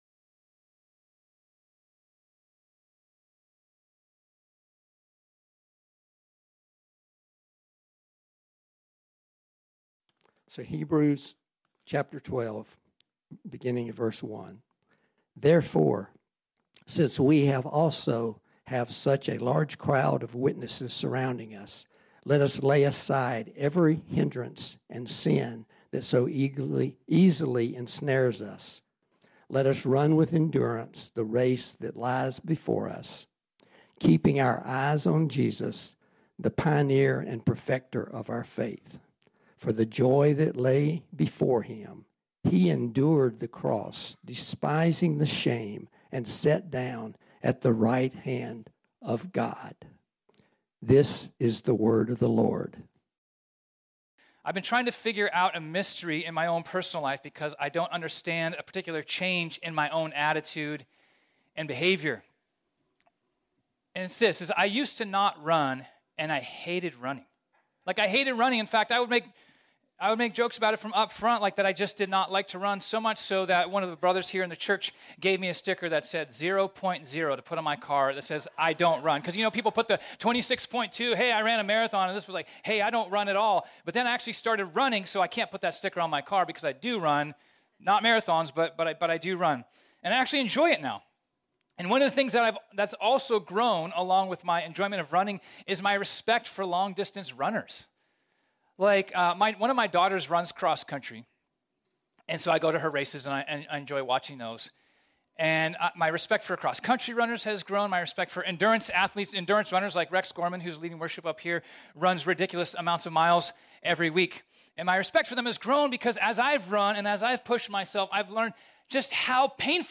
This sermon was originally preached on Sunday, February 19, 2023.